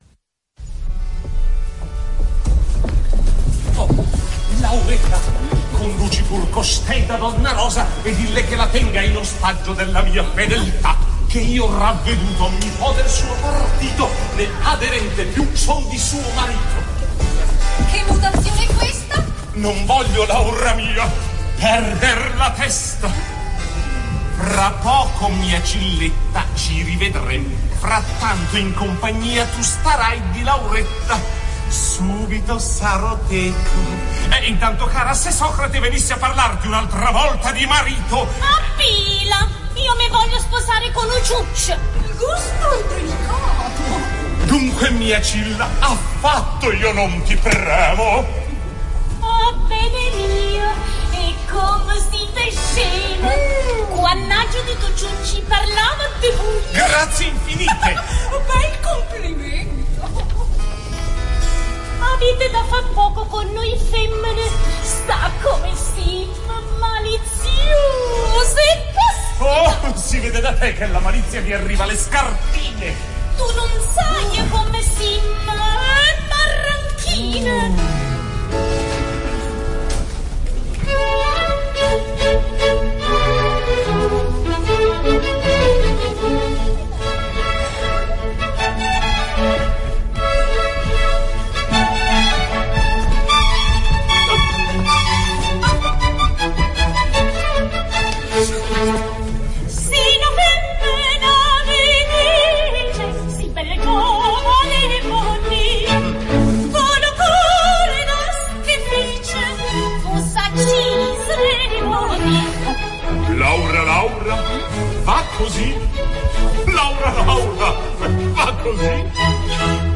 opera completa, registrazione dal vivo.